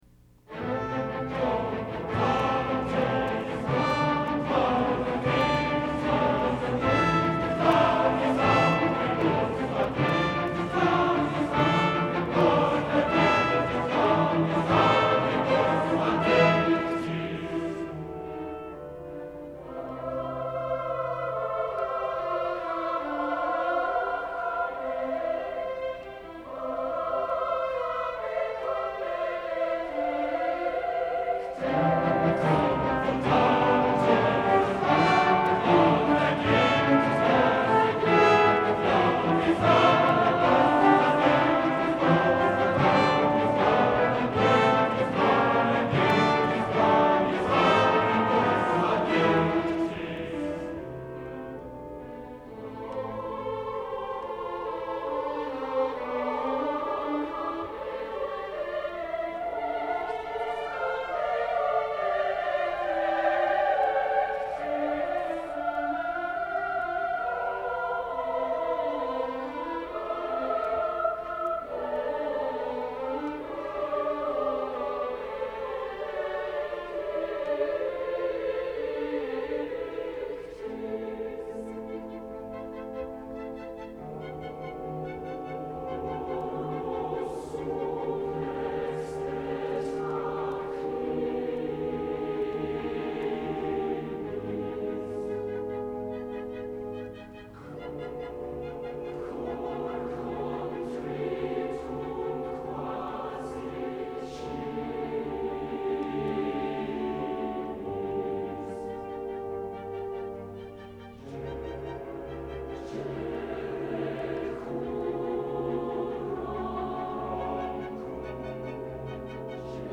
Below are files from the actual performance at the National Cathedral!